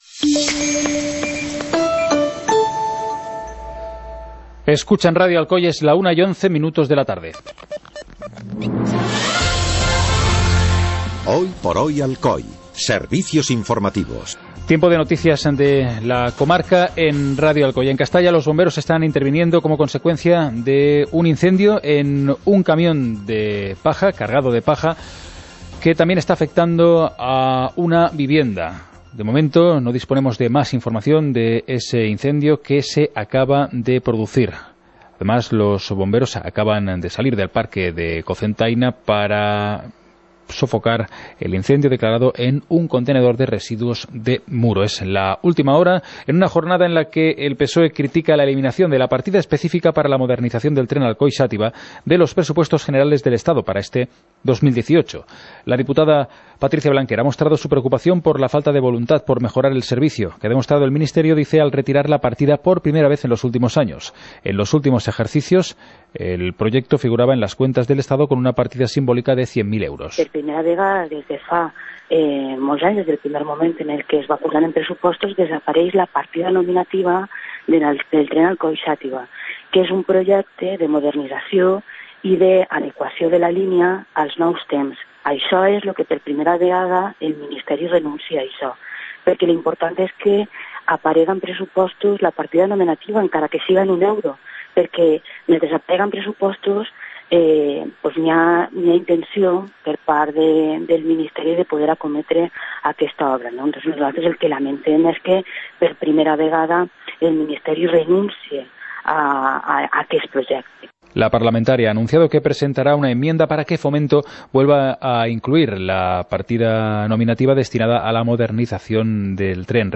Informativo comarcal - viernes, 06 de abril de 2018